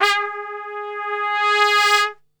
G#2 TRPSWL.wav